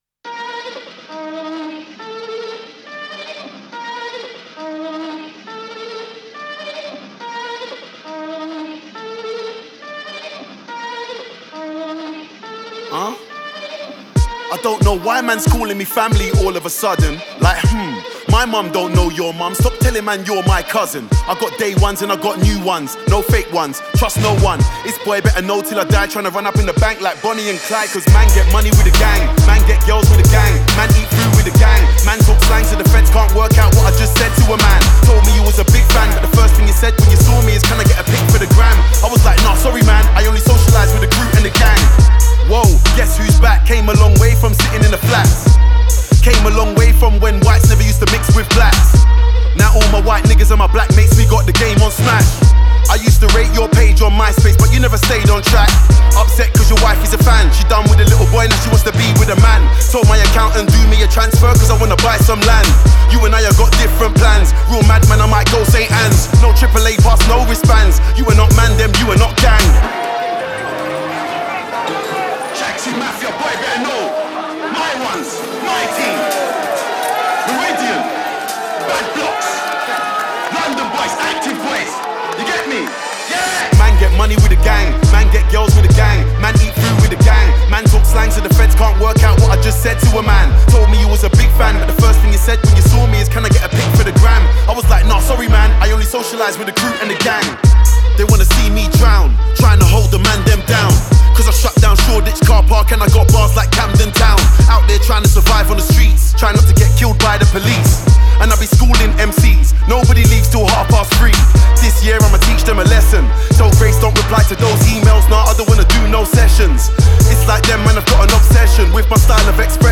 Жанр: Rap.